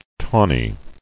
[taw·ny]